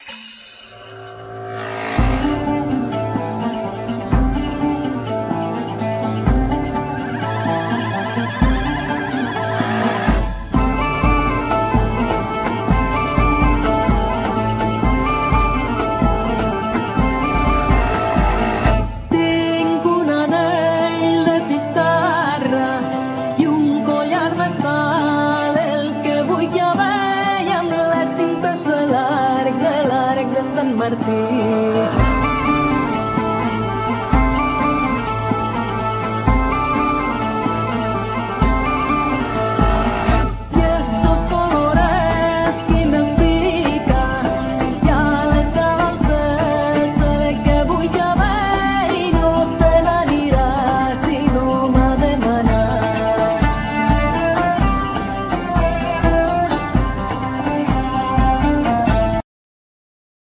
Viella,Rebec,Cello